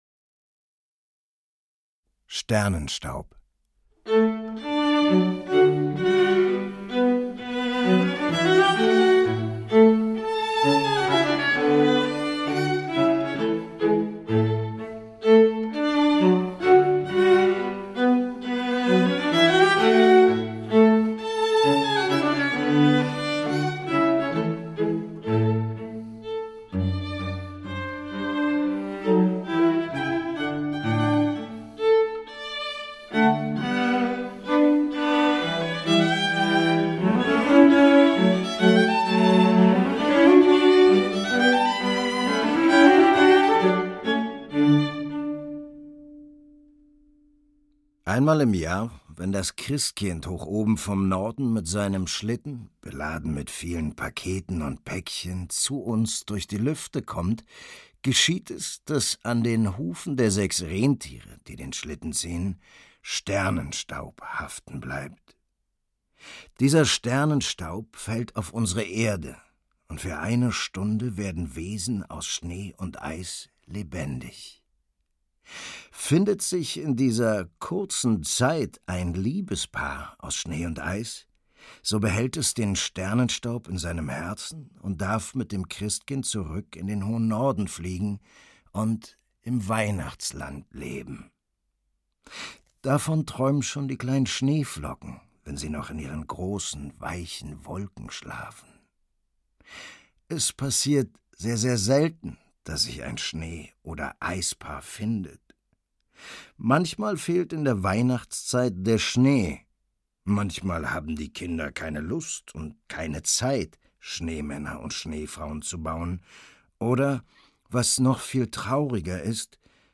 Benno Fürmann und Musik
Erzähler: Benno Fürmann
Die Musik für Violine, Viola und Violoncello ist von Wolfgang Amadeus Mozart, Joseph Haydn, Ludwig van Beethoven, Heitor Villa-Lobos, Benjamin Britten. u.a.